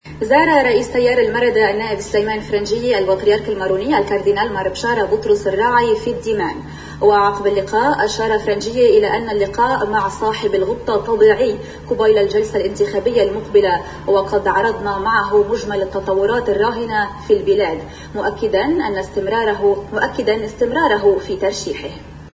وأكد فرنجية في دردشة إعلامية أن “الأجواء كانت ممتازة”، لافتا إلى أن “اللقاء مع صاحب الغبطة طبيعي قبيل الجلسة الإنتخابية المقبلة، وقد عرضنا معه مجمل التطورات الراهنة في البلاد”.